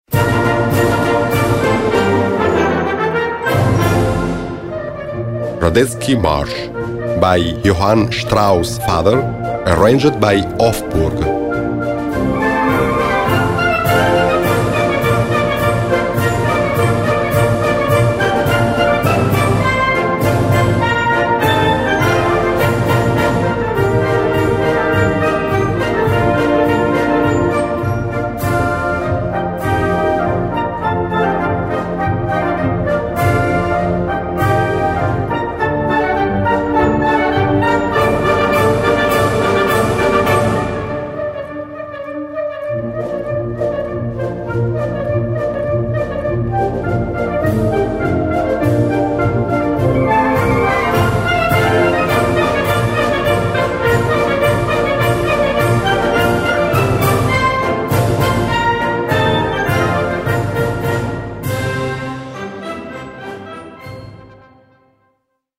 Gattung: Marsch
Besetzung: Blasorchester